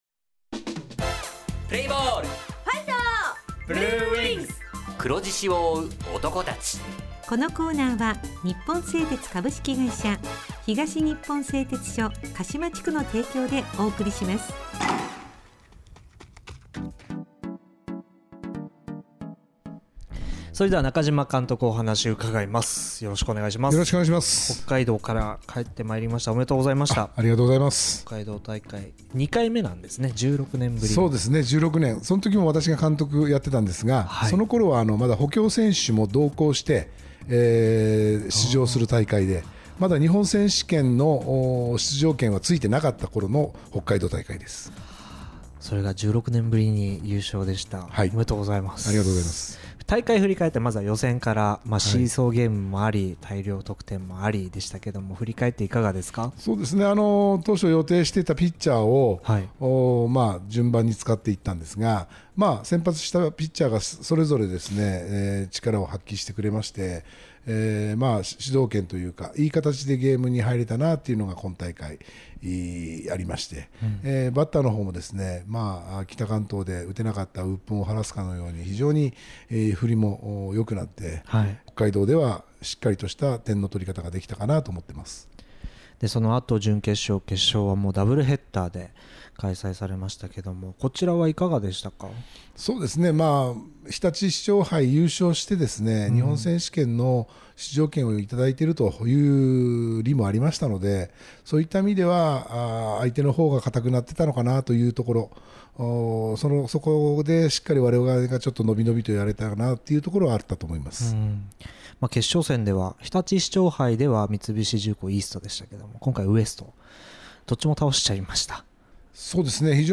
地元ＦＭ放送局「エフエムかしま」にて鹿島硬式野球部の番組放送しています。（７６．７ｍｈｚ）